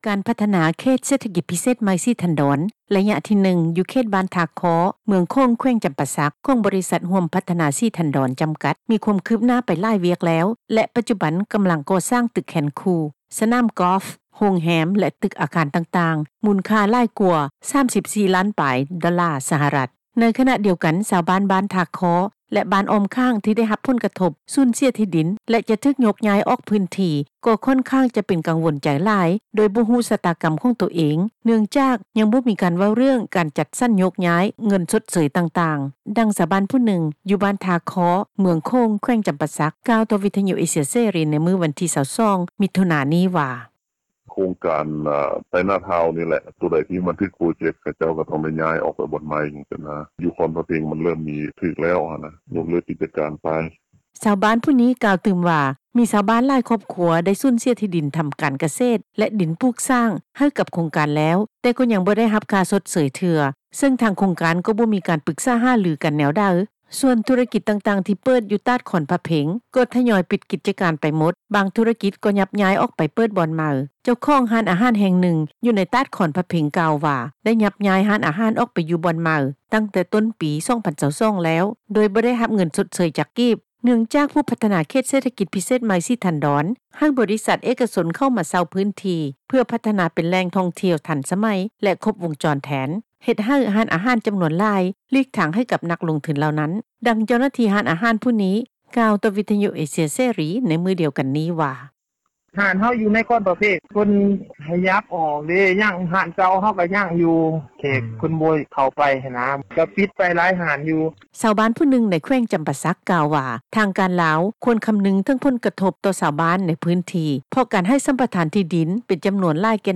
ດັ່ງ ຊາວບ້ານຜູ້ນຶ່ງ ຢູ່ບ້ານ ທ່າຄໍ້ ເມືອງໂຂງ ແຂວງຈໍາປາສັກ ກ່າວຕໍ່ ວິທຍຸ ເອເຊັຽ ເສຣີ ໃນມື້ວັນທີ 22 ມີຖຸນາ ນີ້ວ່າ:
ດັ່ງ ຊາວລາວຜູ້ນີ້ ກ່າວຕໍ່ວິທຍຸ ເອເຊັຽ ເສຣີ ໃນມື້ດຽວກັນນີ້ວ່າ: